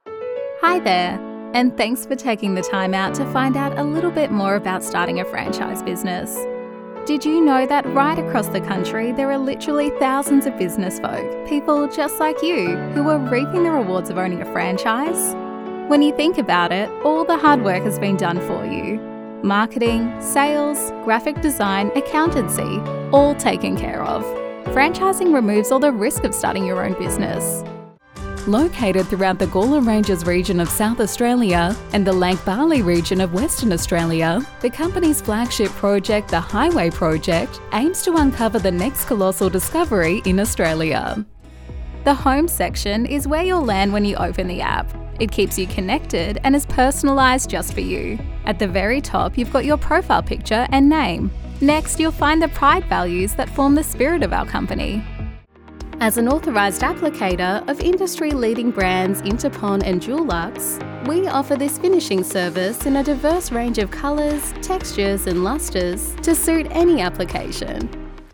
Australian female voiceover artist, with a voice often described as:
Recording from my sound engineer approved home recording studio
Corporate
Trustworthy, Professional